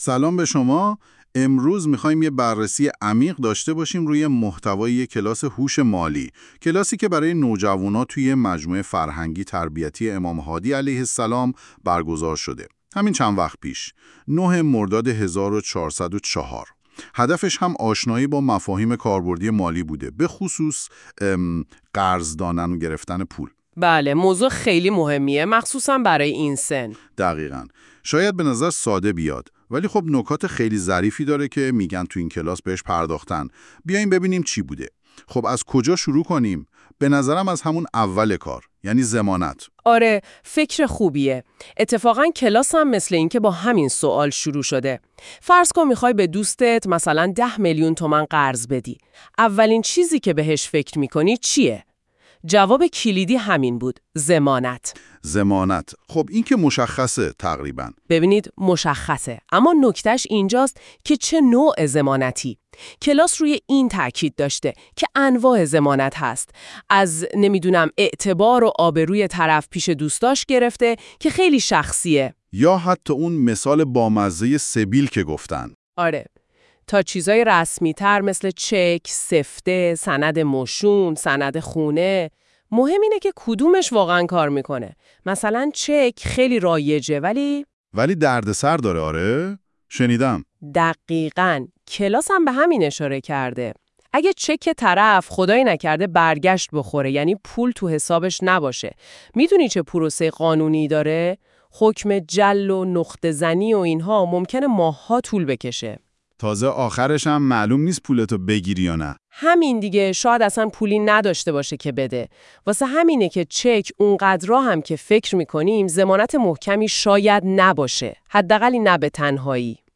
خلاصه این کلاس در قالب پادکست
[با توجه به اینکه پادکست توسط هوش مصنوعی تولید می شود، ممکن است برخی از کلمات اشتباه تلفظ شود؛ همچنین ممکن است برخی مباحث حلقه در پادکست ذکر نشده و یا مطالبی خارج از حلقه در آن آورده شده باشد]